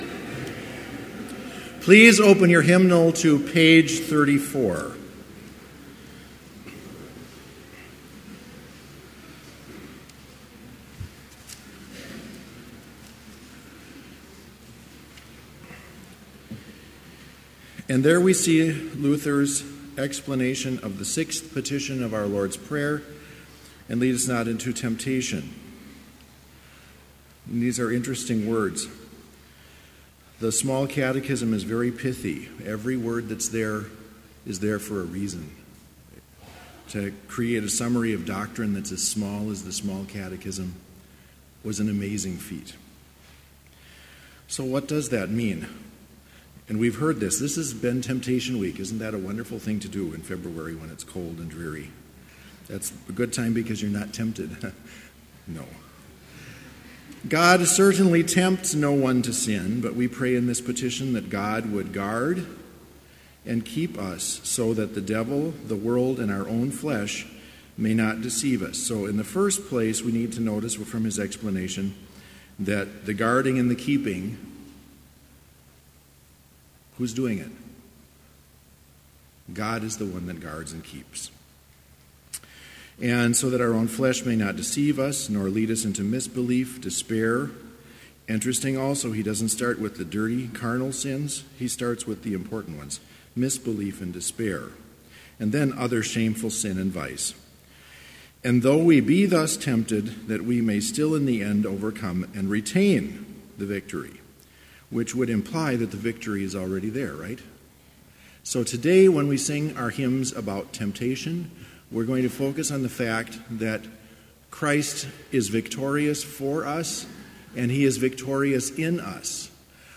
Complete service audio for Chapel - February 22, 2018